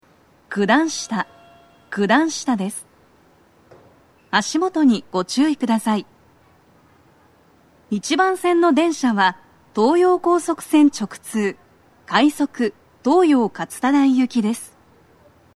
スピーカー種類 BOSE天井型
足元注意喚起放送が付帯されており、粘りが必要です。
女声
到着放送2